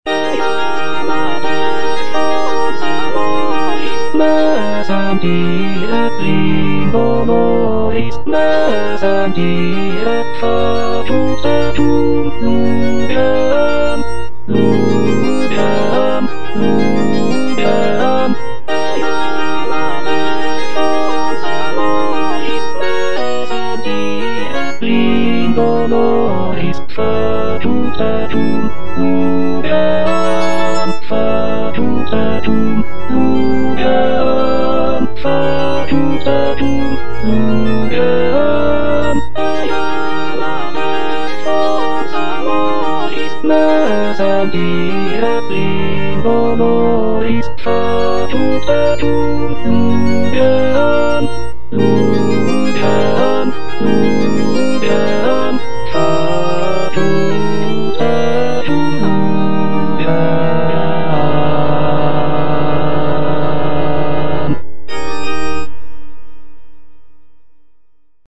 Baritone/Bass (Emphasised voice and other voices) Ads stop